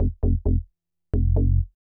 bass01.wav